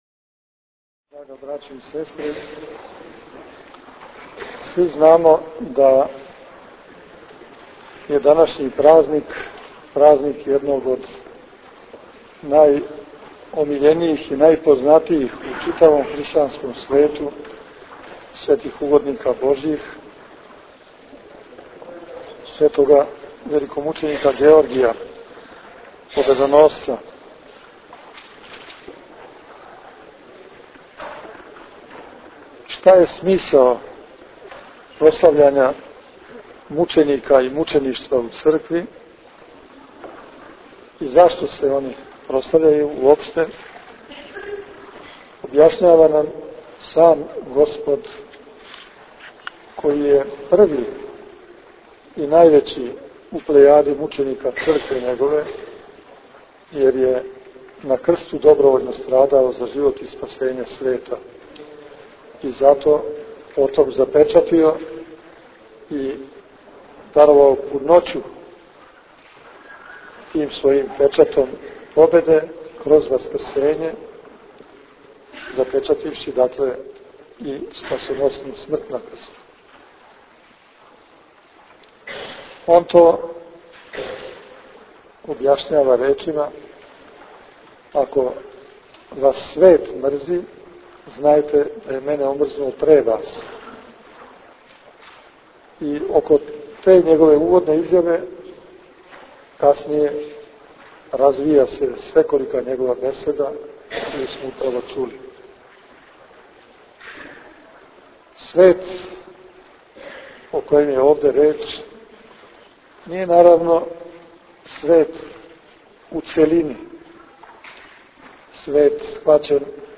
Беседа Владике Иринеја Галерија Фотографија